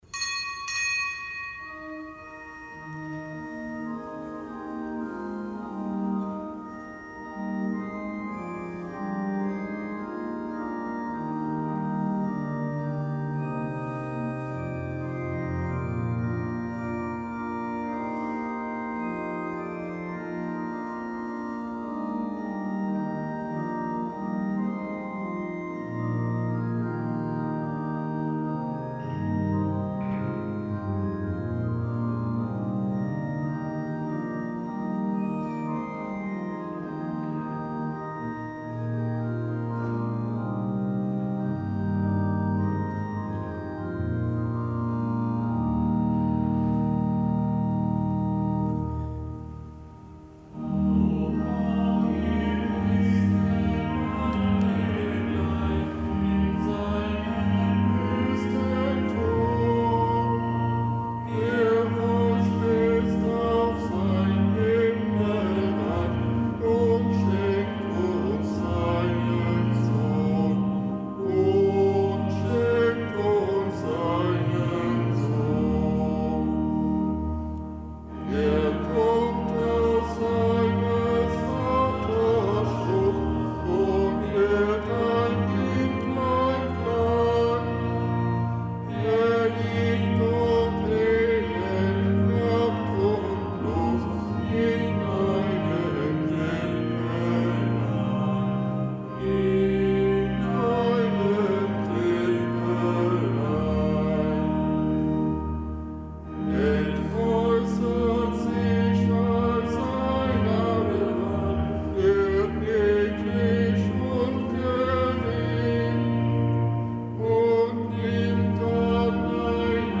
Kapitelsmesse aus dem Kölner Dom am Gedenktag des Heiligen Basilius dem Großen, und dem Heiligen Gregor von Nazianz.